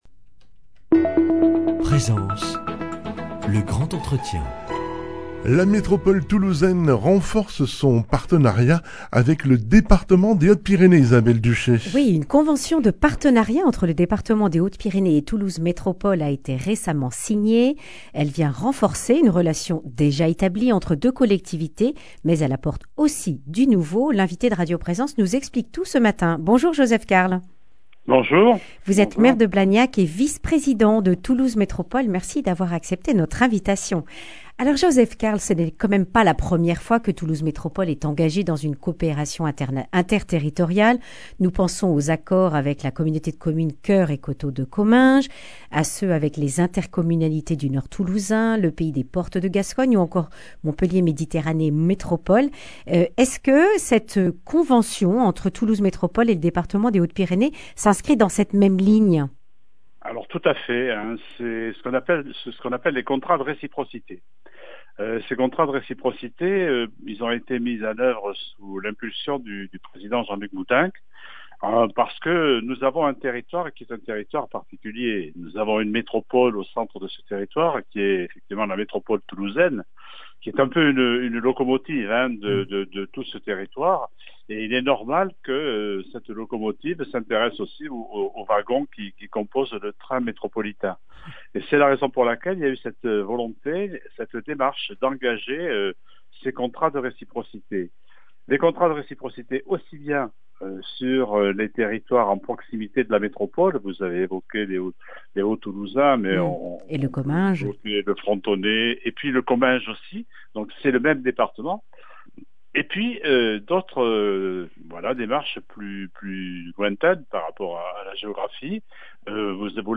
Elle concerne trois domaines principaux : l’alimentation, le tourisme et l’innovation. Joseph Carles, vice président de Toulouse Metropole et maire de Blagnac présente les enjeux d’’une telle coopération.
Le grand entretien